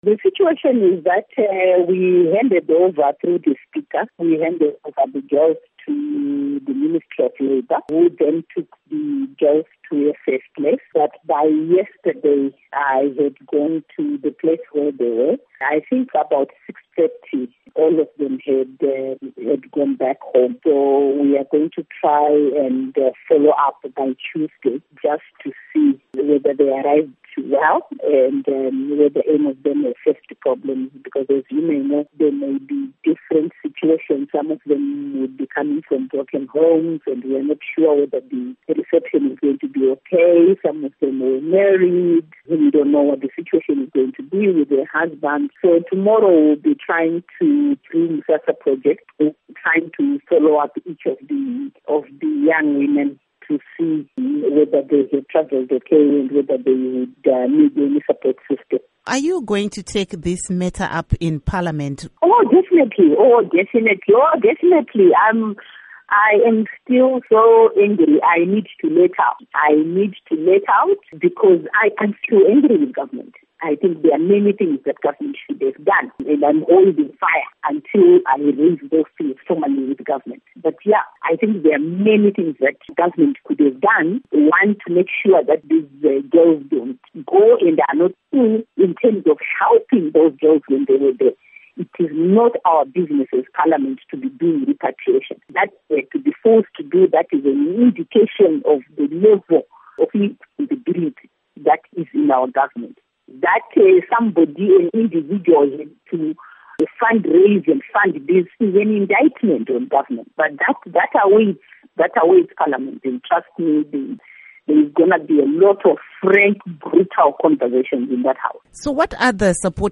Interview Priscilla Misihairambwi-Mushonga